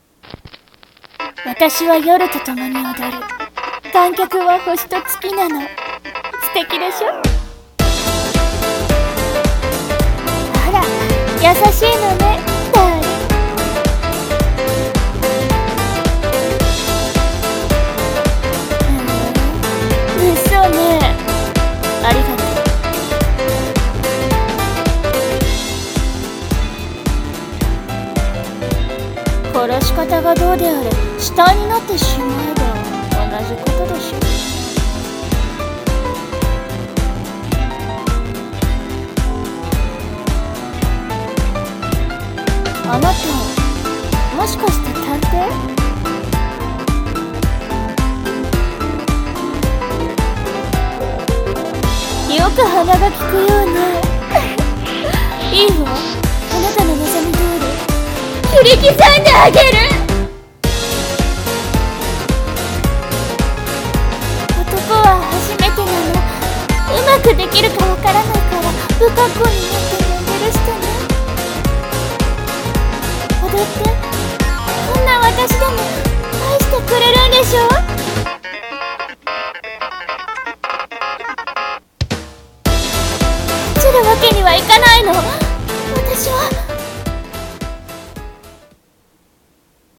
アニメCM風声劇【切り裂きジャックのダンス・マカブル